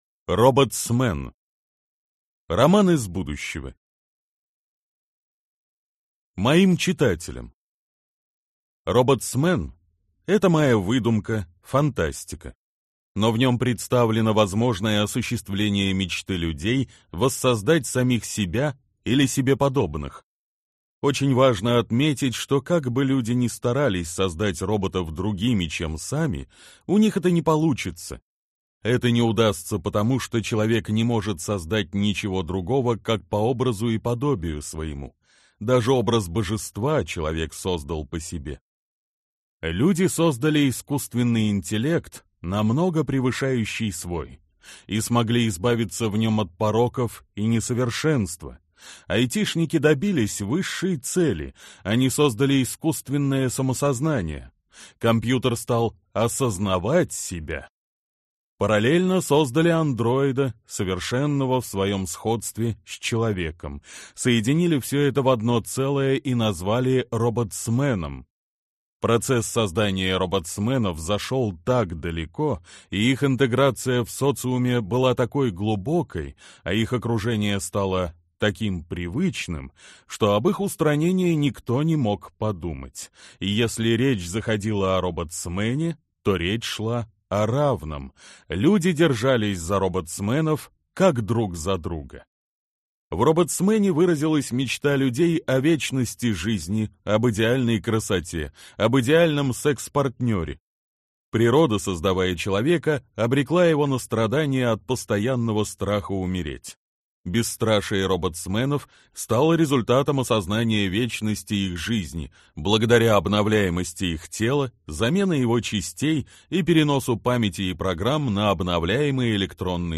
Аудиокнига Роботсмэн. Роман из будущего | Библиотека аудиокниг